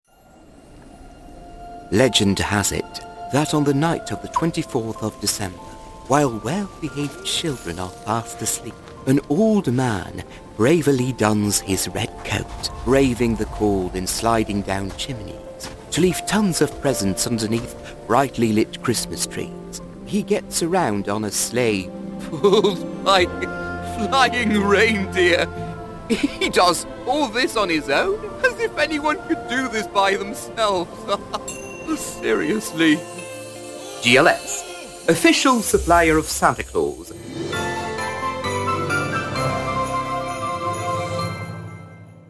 voix-off Spot internet En NL, Fr, Anglais